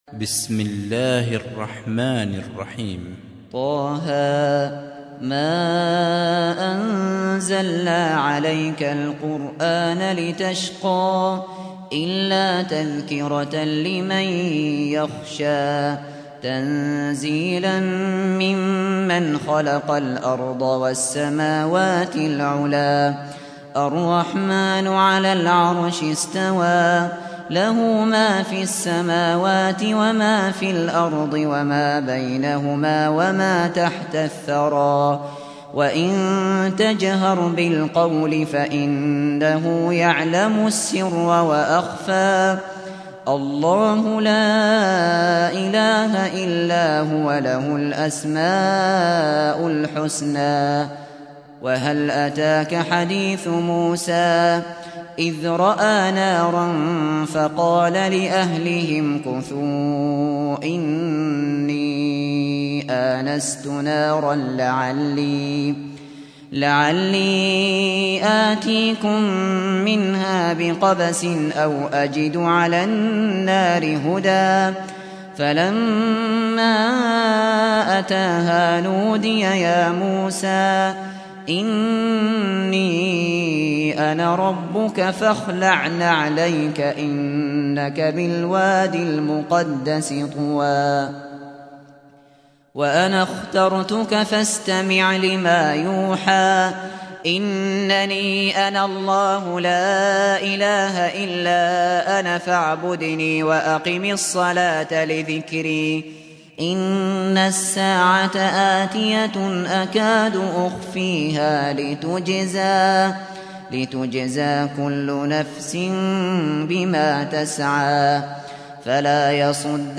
سُورَةُ طه بصوت الشيخ ابو بكر الشاطري